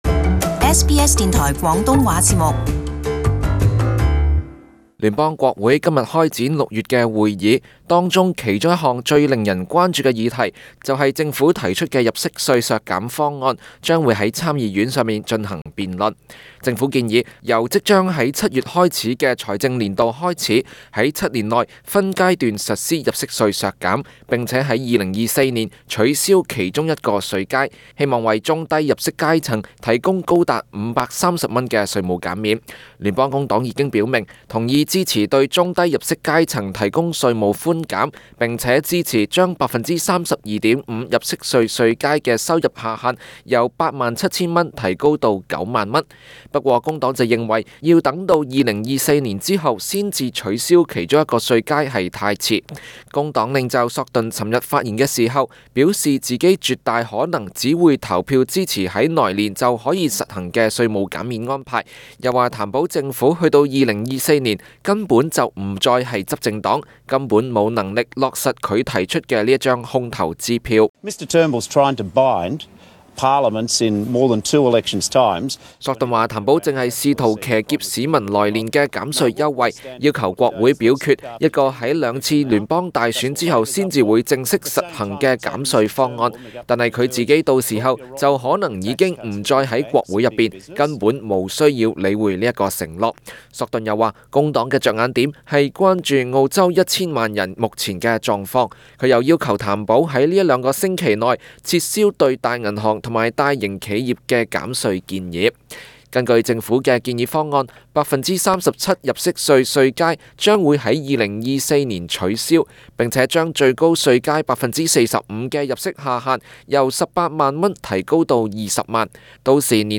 【時事報導】參議院本週審議減稅方案